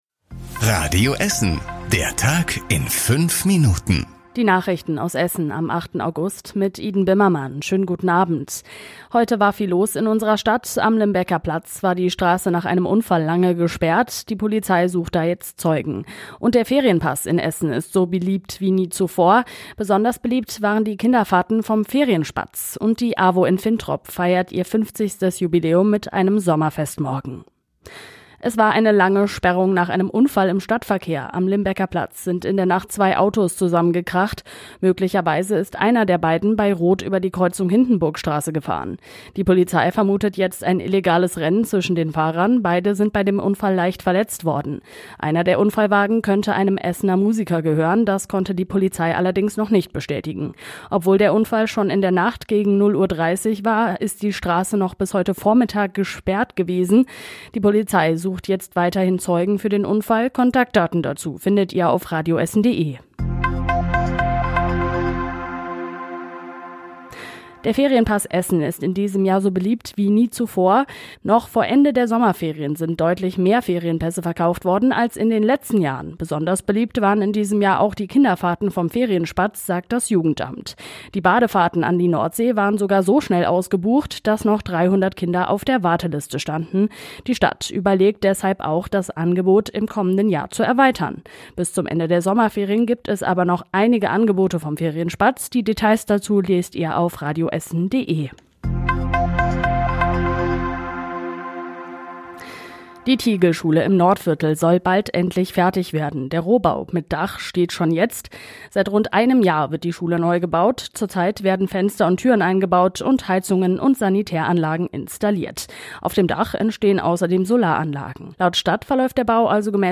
Die wichtigsten Nachrichten des Tages in der Zusammenfassung